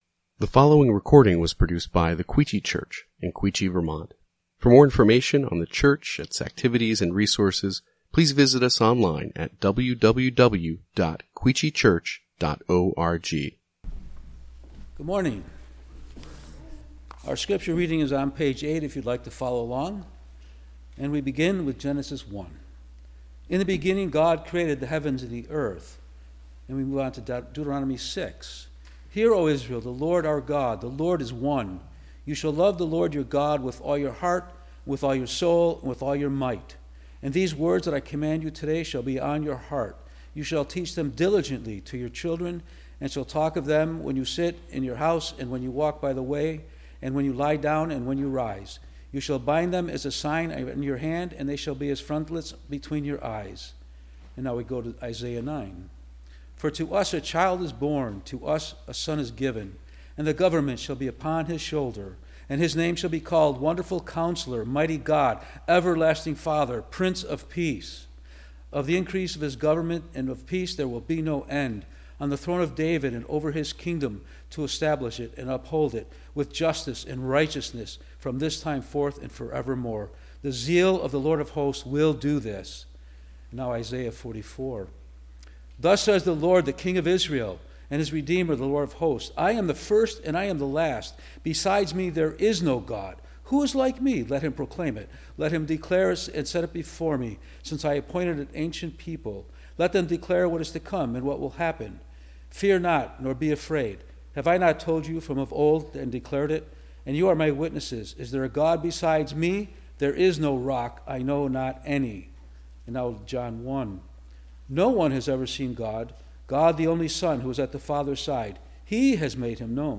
Quechee Church | Sermon Categories Holiday